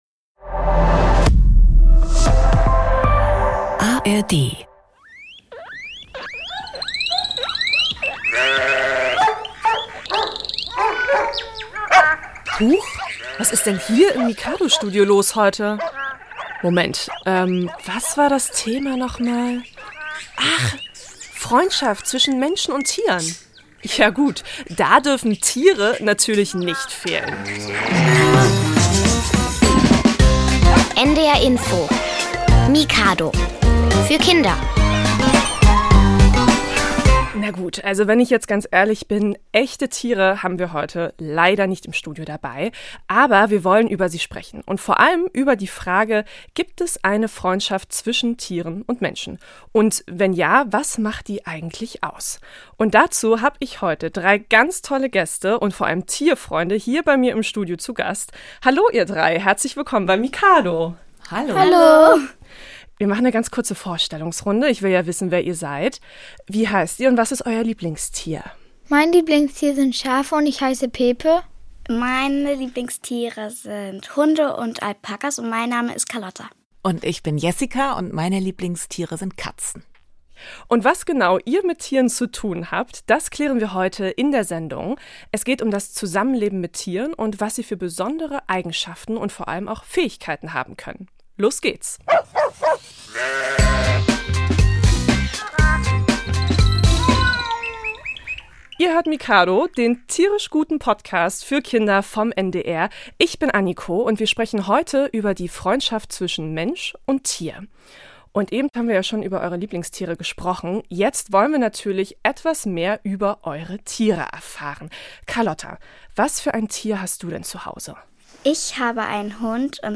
Aktuelle Themen mit Aha-Effekt - zum Lachen, Lernen und Weitersagen. Bei uns kommen Kinder und Experten zu Wort, es gibt Rätsel, Witze, Reportagen, Buch- und Basteltipps, Experimente und Musik.